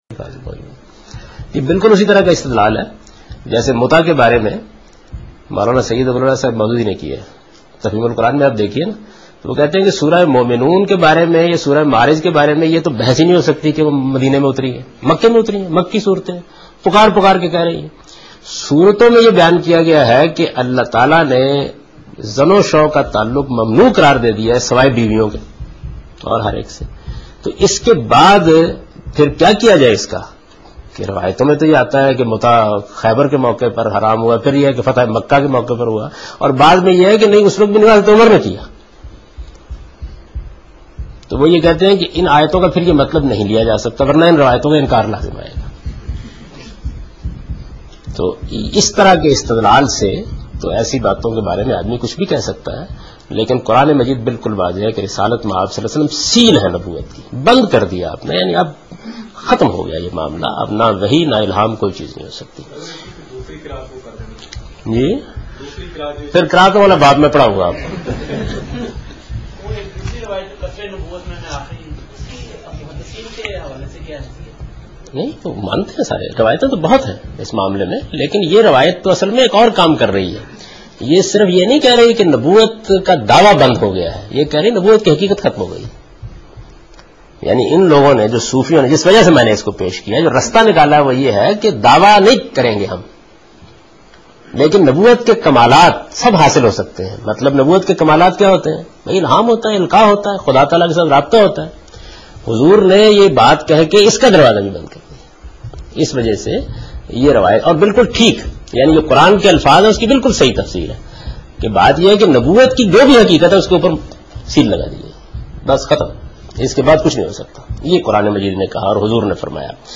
Javed Ahmed Ghamidi: From Meezan Lectures